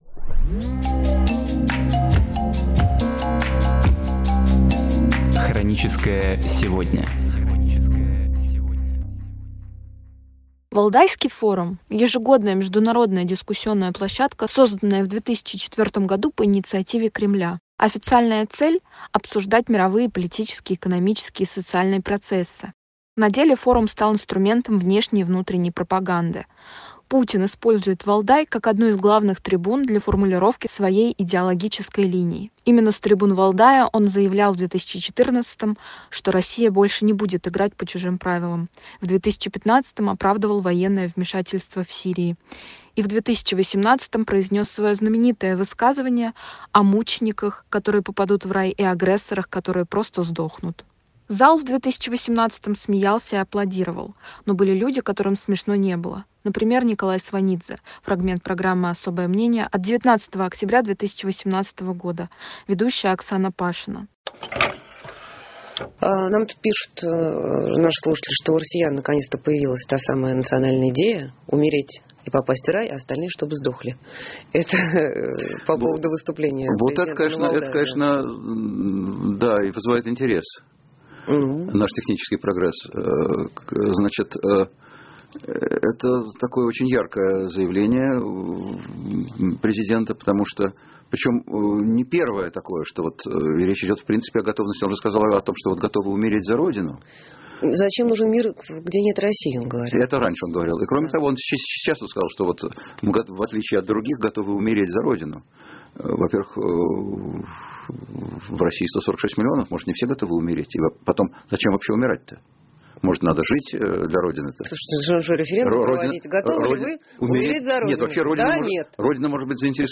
Программы из архива «Эха Москвы»